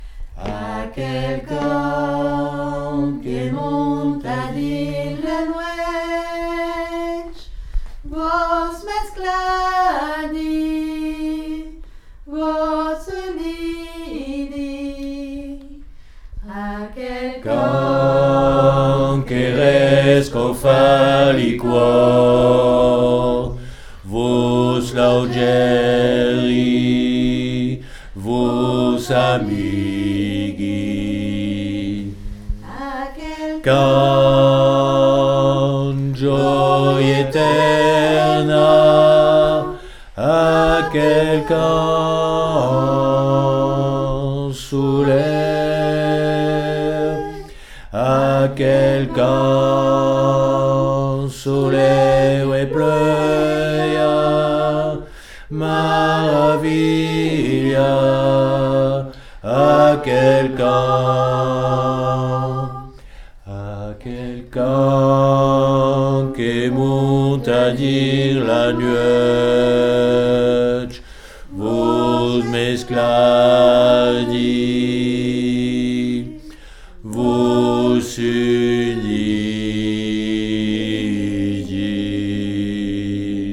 Aquel_cant_BASSE.mp3